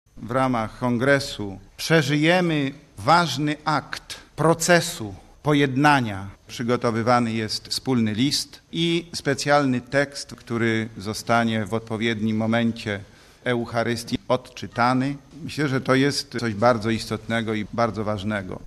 Mówi biskup Piotr Jarecki: